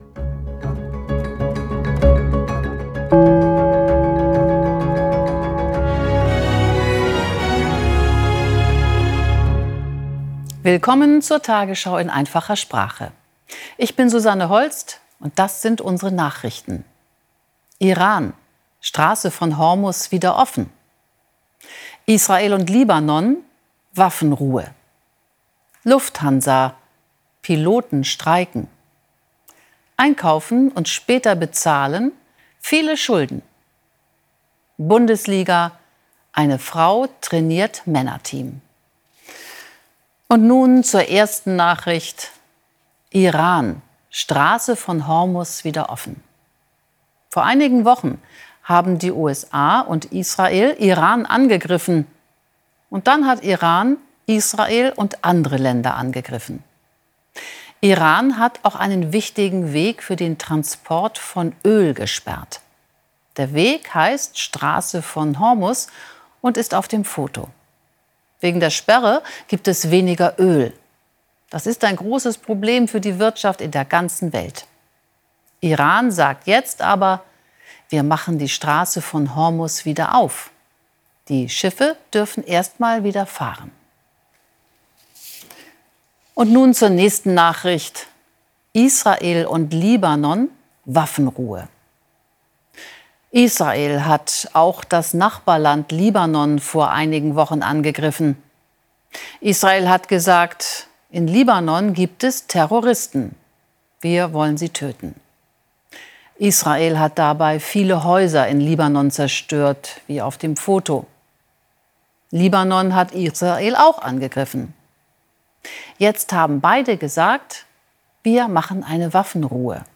Genres: Education, News